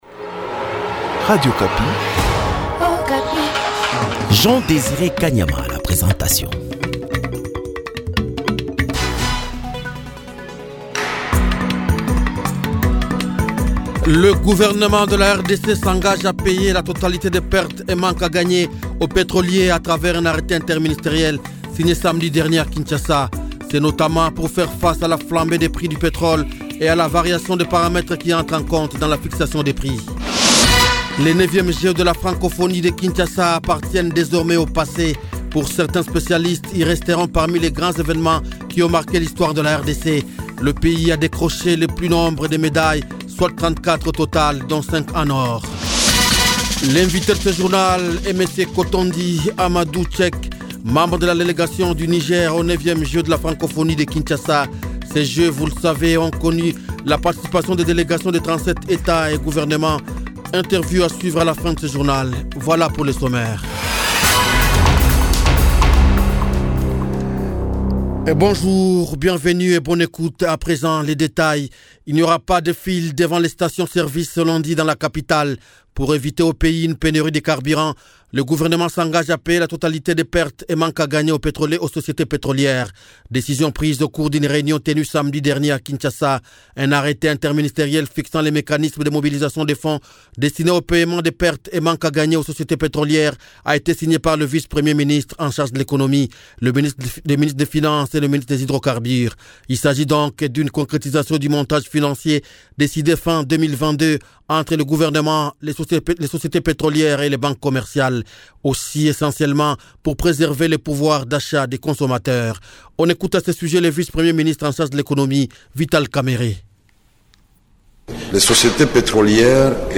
Journal Francais